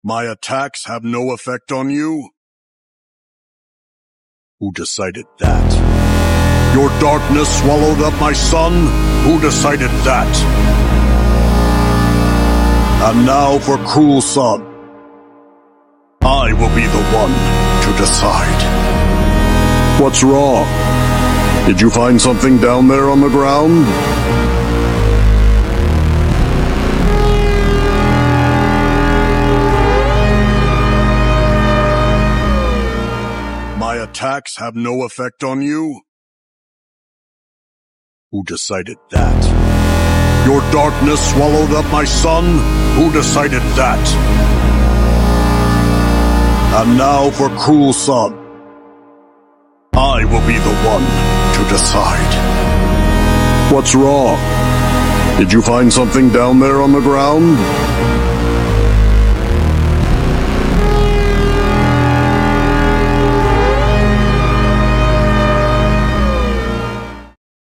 Fully build motor + s257 sound effects free download